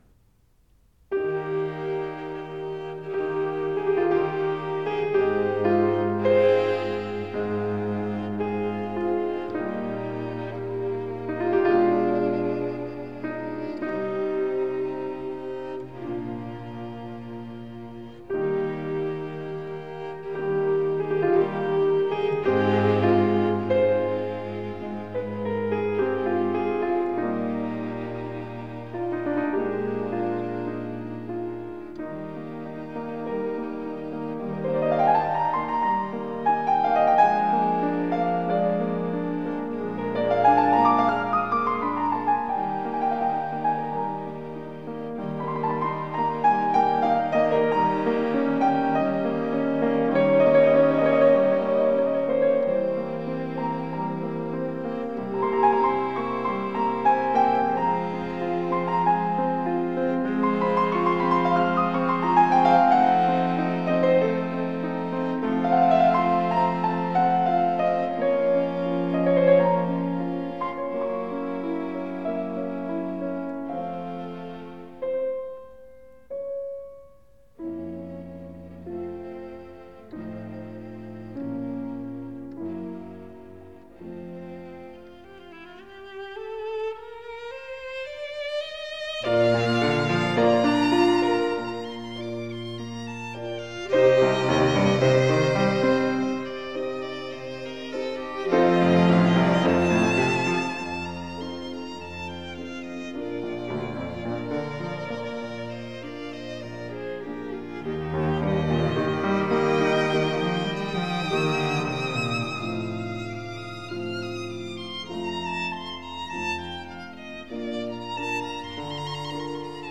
화려하면서도 담백하고, 각각의 연주가 빛나면서도 어느 한쪽으로 치우침이 전혀없는,